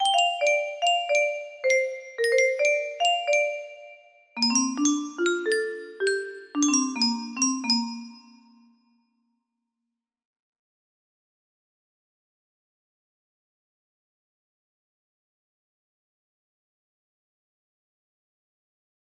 Melody 1 music box melody
Full range 60